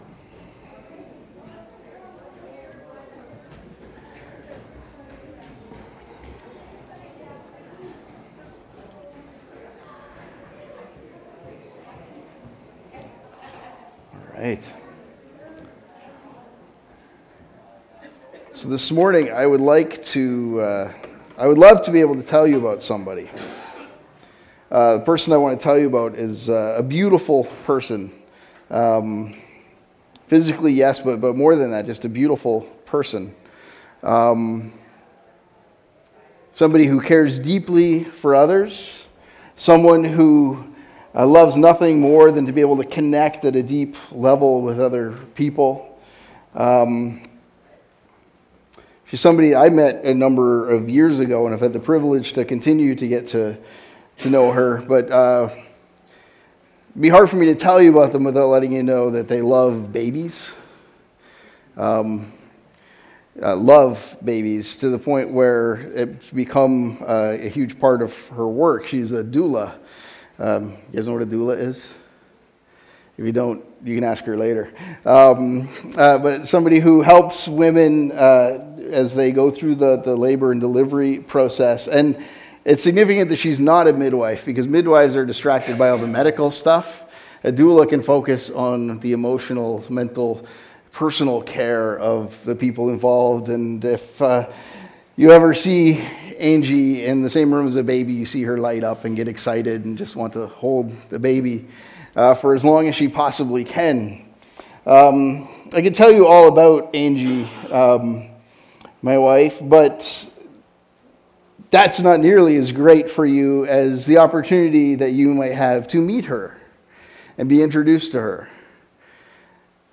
2019 Current Sermon Jesus is ...